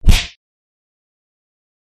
Sweep Kick
Sweep Kick sound effect for fight or game sound effects.